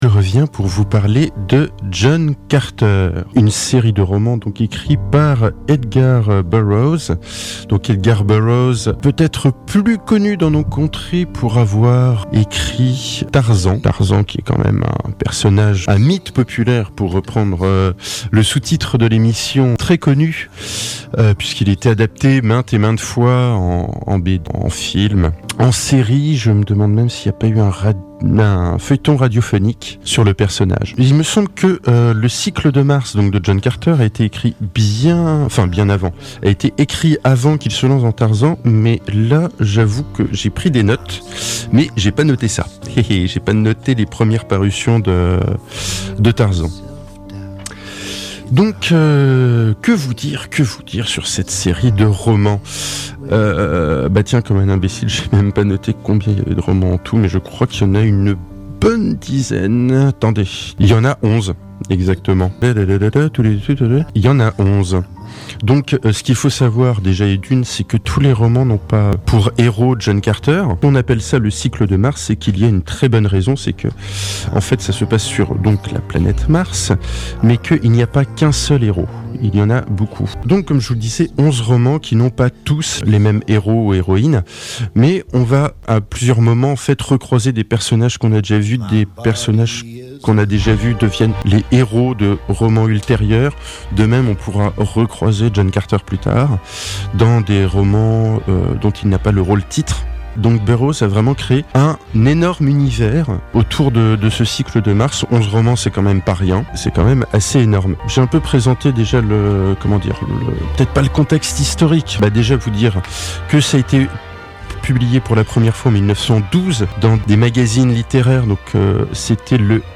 des romans cultes multi-adaptés en bande dessinée et bientôt au cinéma. et chapeau pour avoir géré seul cette chronique dans des conditions techniquement très difficiles.